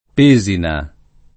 [ p %@ ina ]